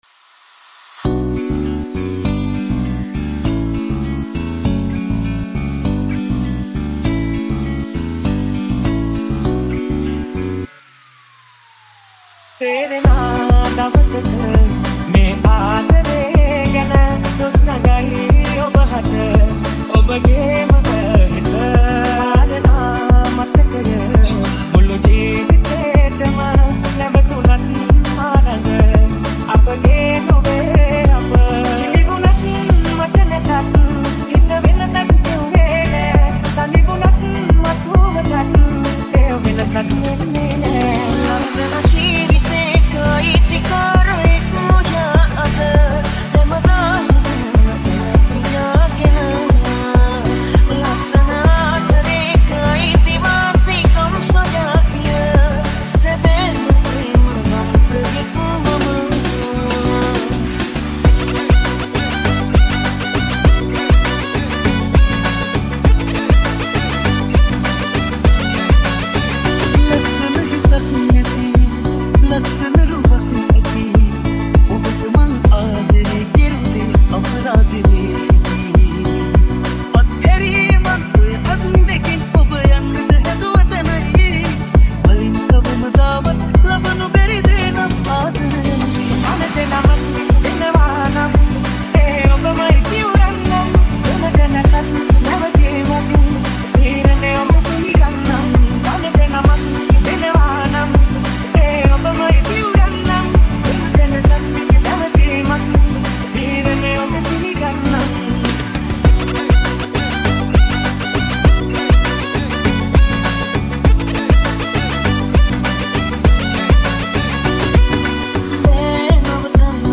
Sinhala DJ Songs | Sinhala Remix